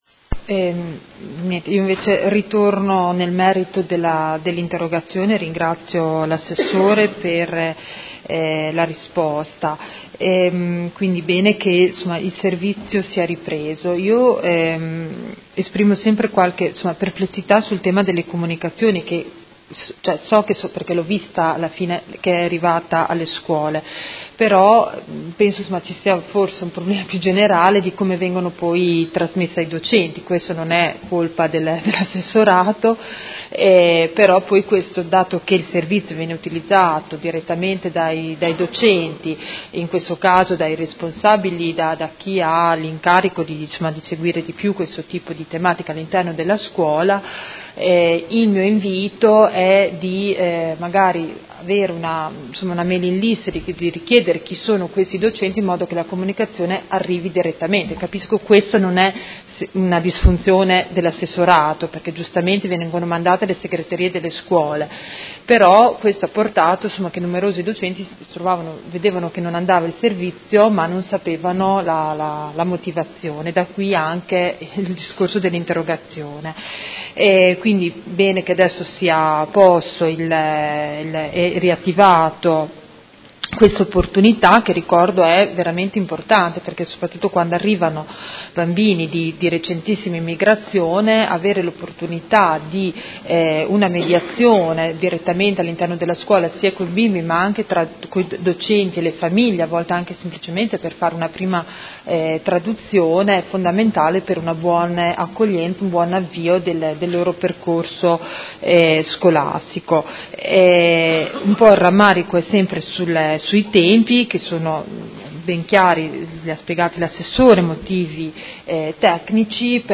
Seduta del 10/01/2019 Replica a risposta Assessore Cavazza. Interrogazione della Consigliera Baracchi (PD) avente per oggetto: Sospensione servizio mediazione linguistica culturale – Memo.